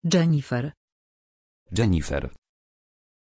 Aussprache von Jennifer
pronunciation-jennifer-pl.mp3